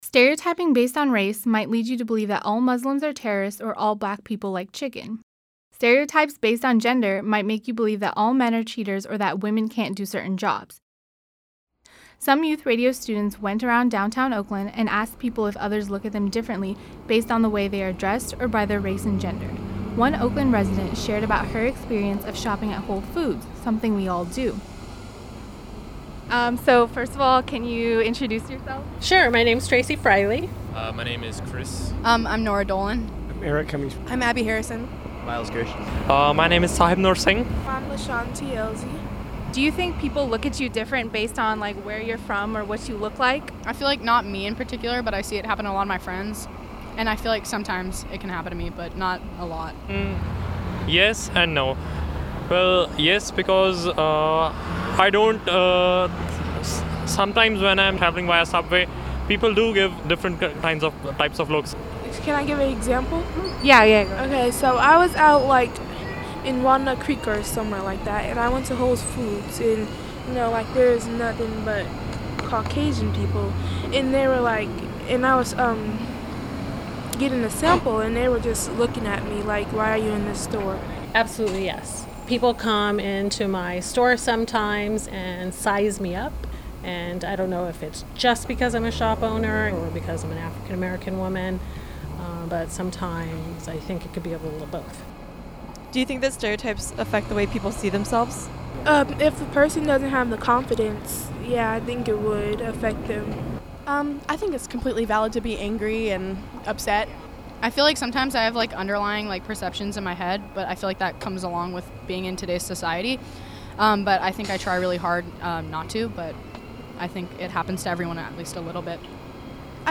Some Youth Radio students went around Downtown Oakland and asked people if others look at them differently based on the way they are dressed, or by their race and gender.  One Oakland resident shared about her experience of shopping at Whole Foods, something we all do.